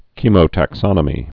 (kēmō-tăk-sŏnə-mē, kĕmō-)